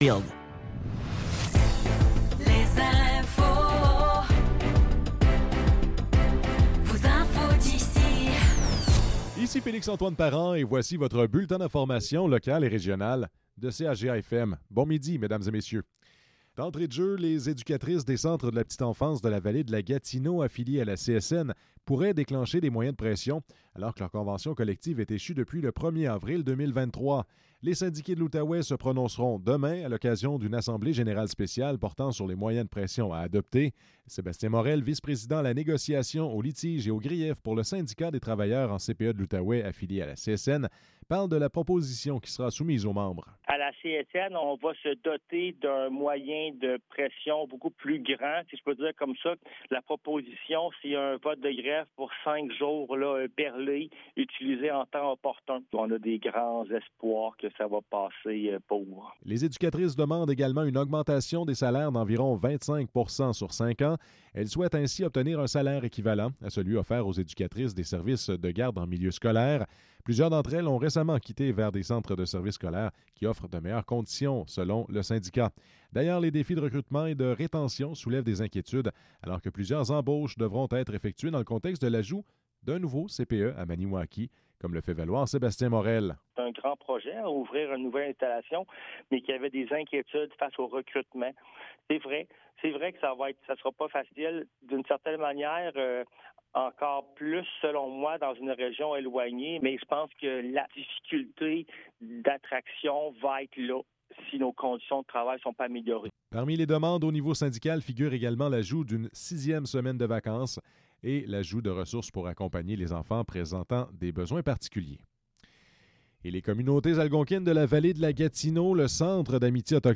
Nouvelles locales - 25 octobre 2024 - 12 h